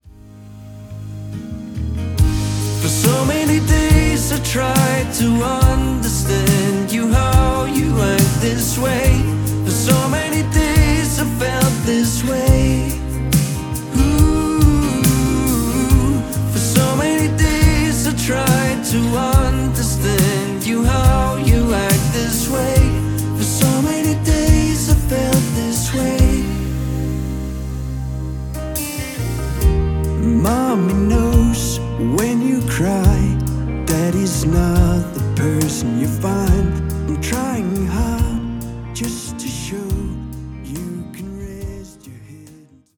• Folk
• Singer/songwriter